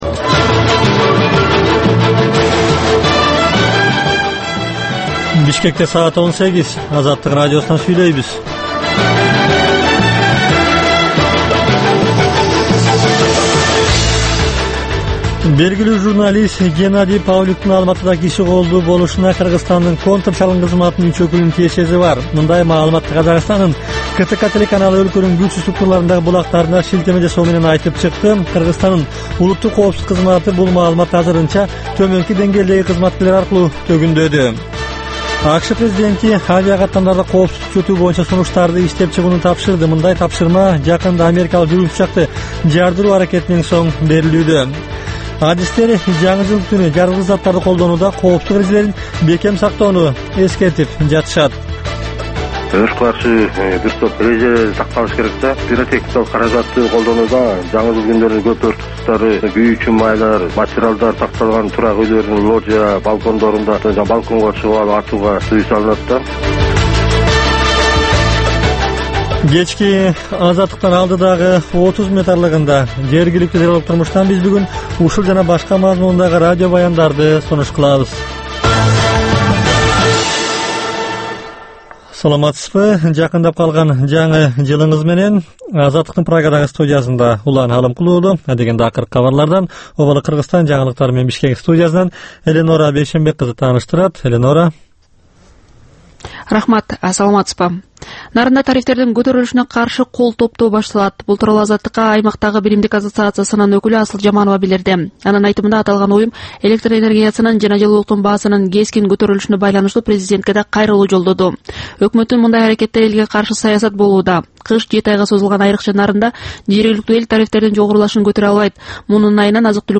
"Азаттык үналгысынын" бул кечки алгачкы берүүсү жергиликтүү жана эл аралык кабарлардан, репортаж, маек, баян жана башка берүүлөрдөн турат. Бул үналгы берүү ар күнү Бишкек убактысы боюнча саат 18:00ден 18:30га чейин обого түз чыгат.